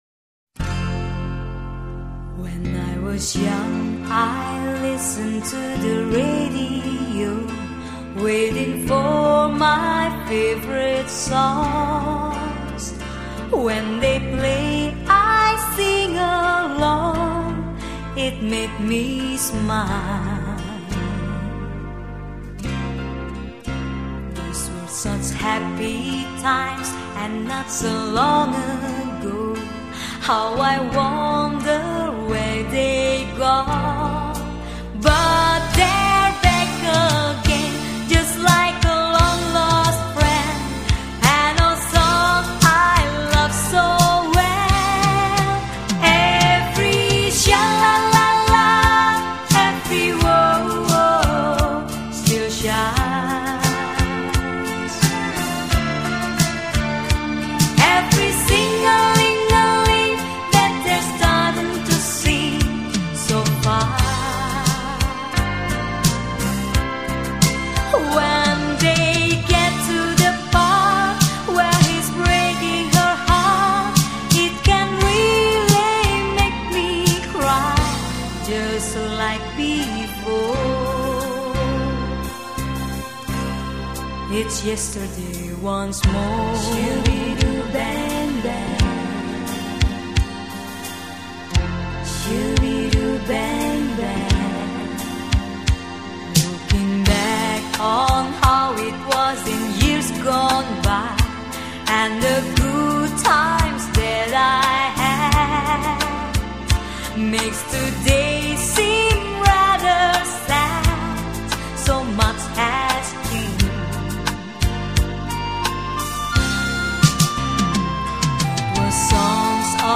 美国乡村音乐历史